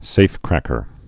(sāfkrăkər)